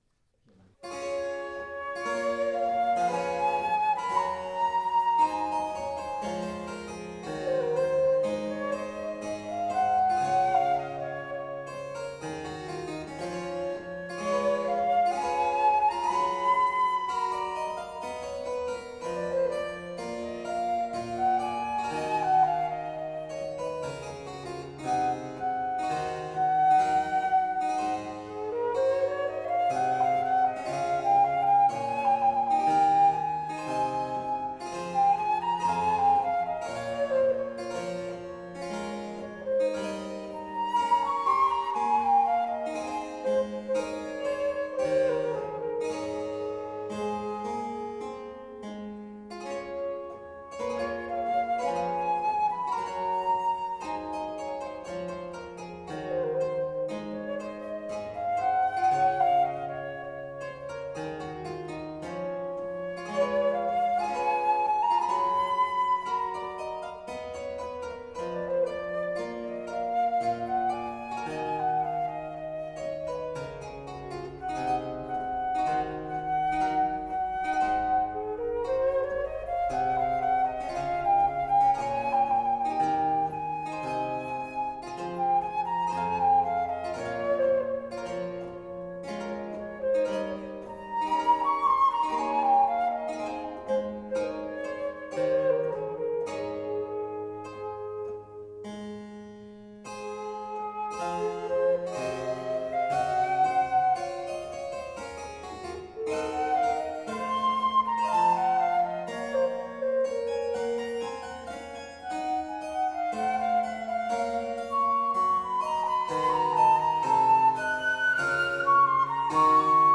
Sonate h-Moll BMW 1030 für Flöte und Cembalo